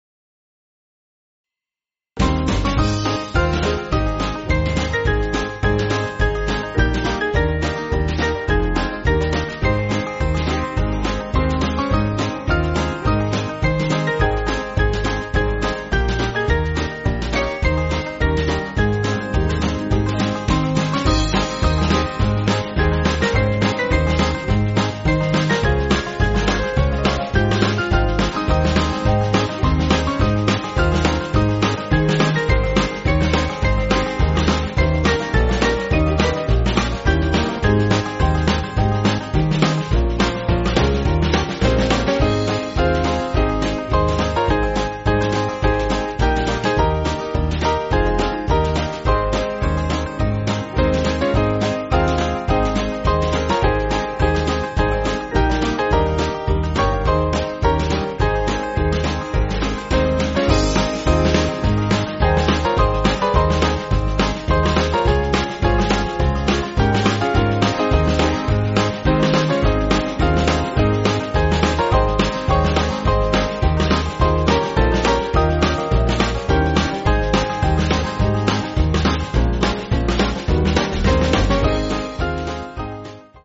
Small Band
(CM)   3/Ab